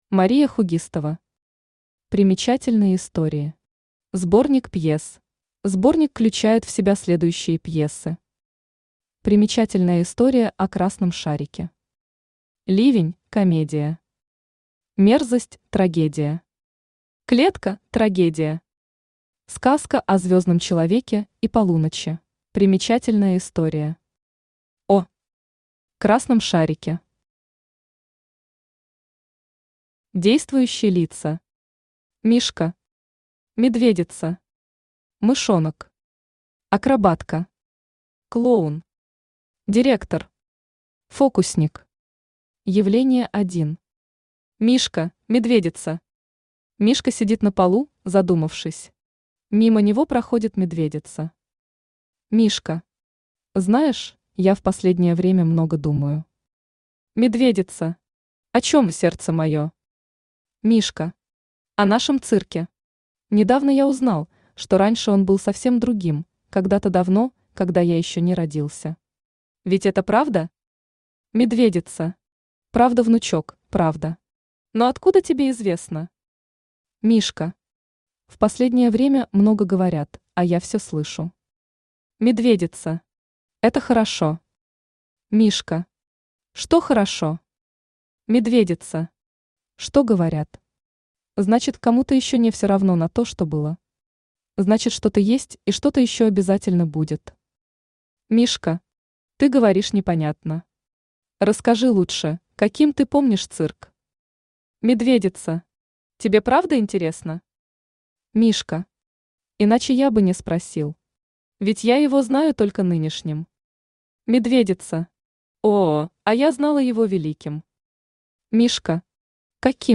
Аудиокнига Примечательные истории. Сборник пьес | Библиотека аудиокниг